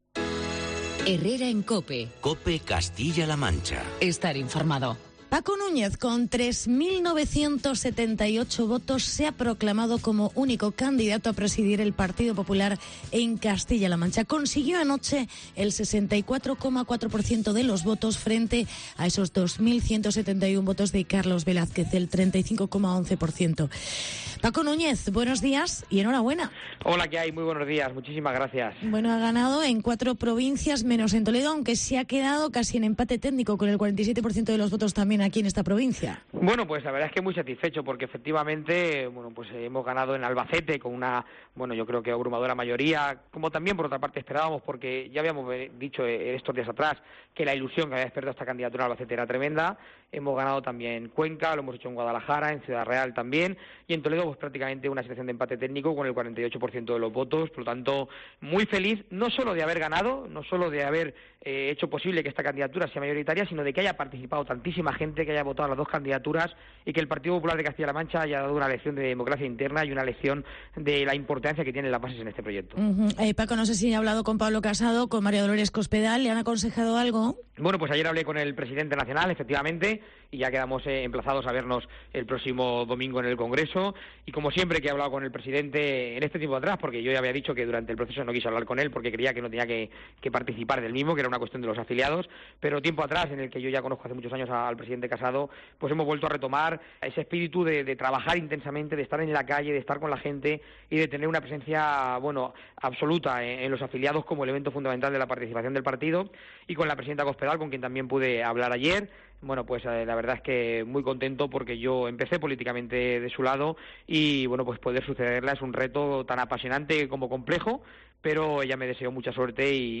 Entrevista con Paco Núñez. Ganador de las primarias del PP CLM .